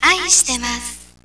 声優